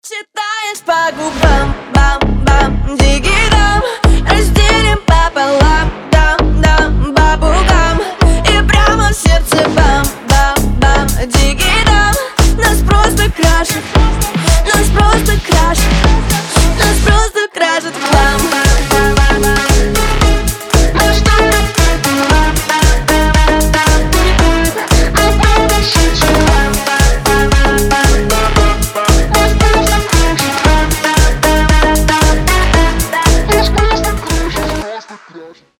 • Качество: 320, Stereo
женский голос
Club House